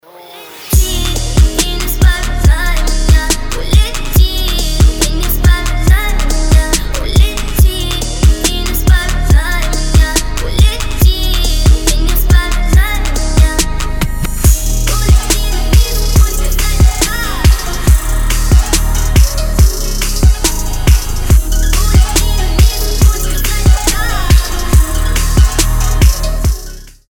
• Качество: 320, Stereo
dance
club
Bass